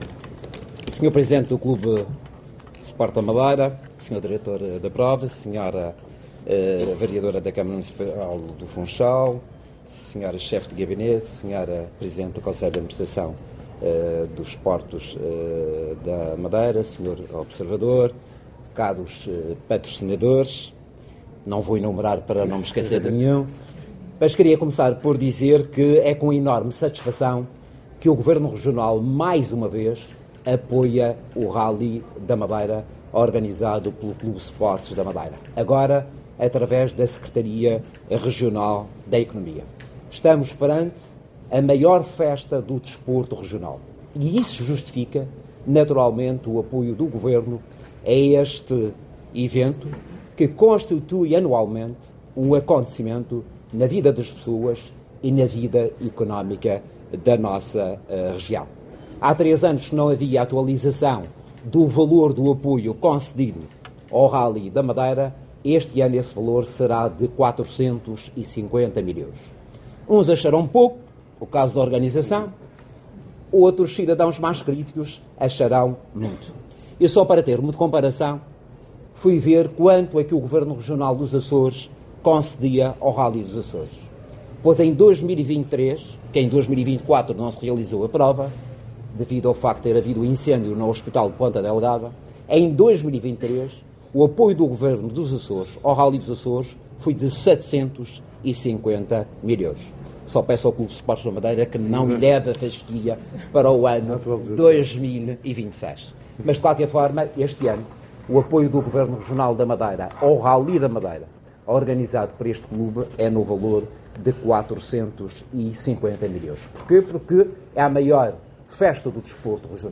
CI apresentação Rali da Madeira_JMR.mp3